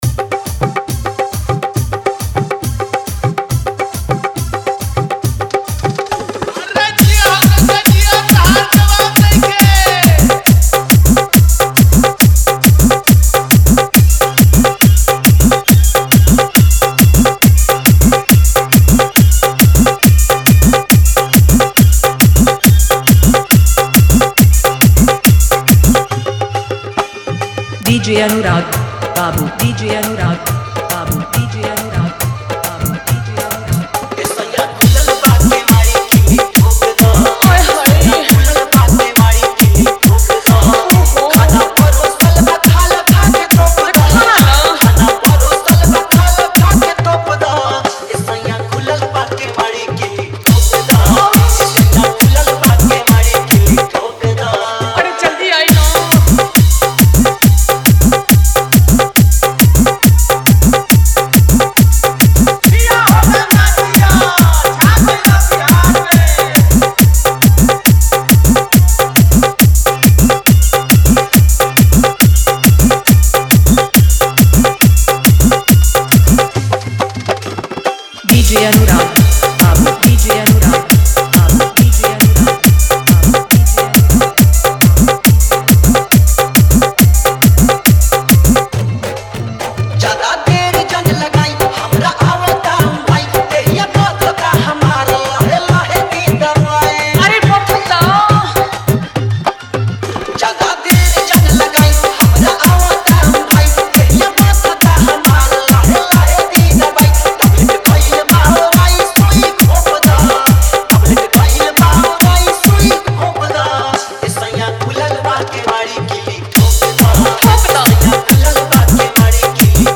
Category : dj remix songs bhojpuri 2025 new